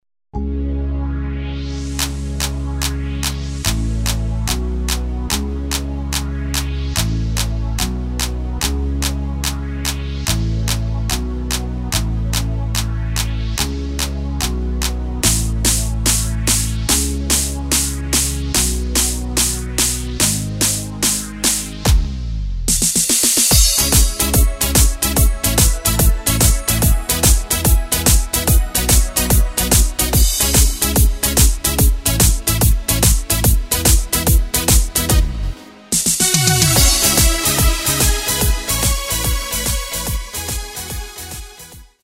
Tempo:         145.00
Tonart:            C#
Playback mp3 mit Lyrics